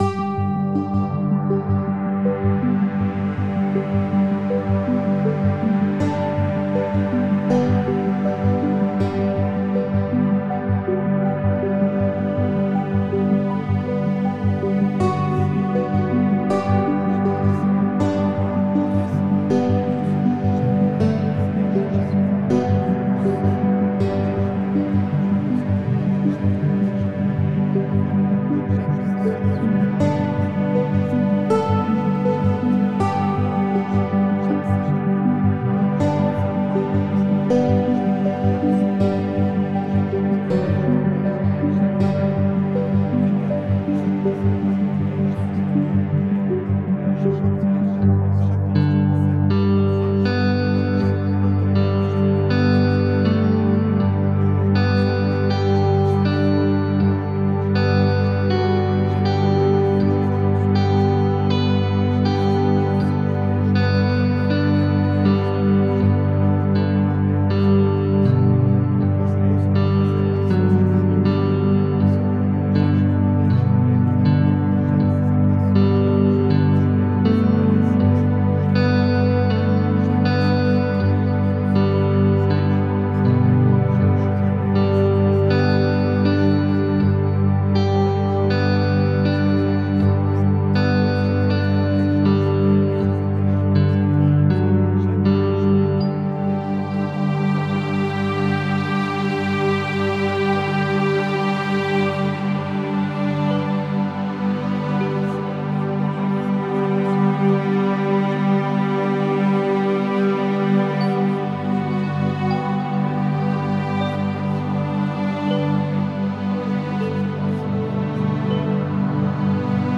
(Version RELAXANTE)
Alliage ingénieux de sons et fréquences curatives, très bénéfiques pour le cerveau.
Pures ondes thêta apaisantes 4Hz de qualité supérieure.
SAMPLE-Guru-social-1-relaxant.mp3